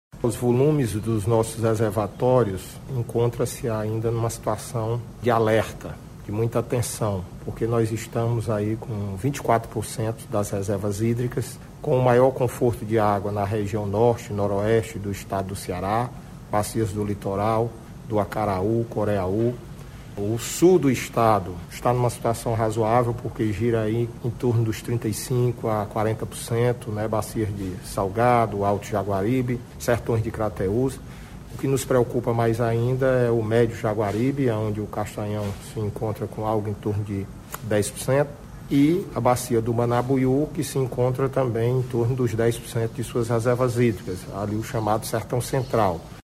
Dentro desta realidade, o secretário dos Recursos Hídricos do Ceará, Francisco Teixeira, faz um panorama sobre a situação hídrica do estado e destaca as regiões mais afetadas pela escassez hídrica.